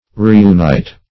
Reunite \Re`u*nite"\, v. t. & i.